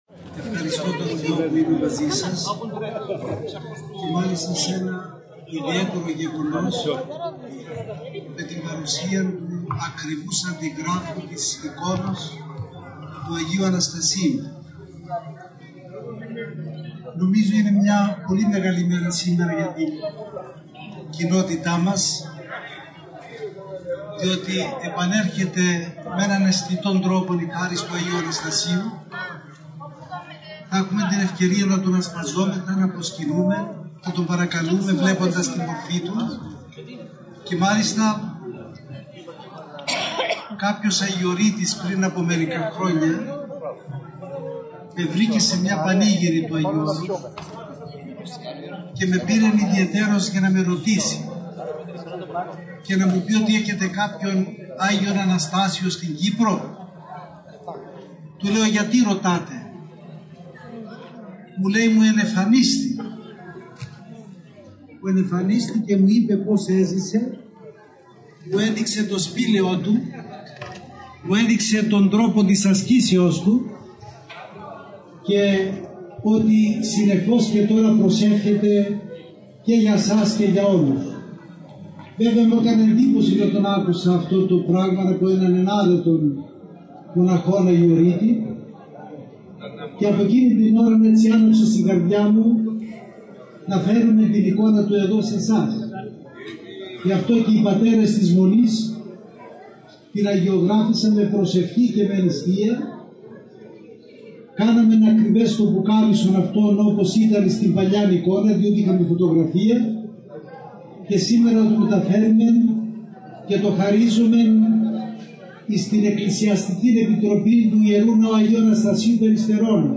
Ο Καθηγούμενος της Ι.Μ.Μ. Βατοπαιδίου Γέροντας Εφραίμ μιλάει για τον Άγιο Αναστάσιο τον Υφαντή και θαυματουργό κατά την επίσκεψή του στην Κύπρο και την προσκόμιση αντιγράφου της ιεράς εικόνος του αγίου στο χωριό Κοφίνου στη Λάρνακα.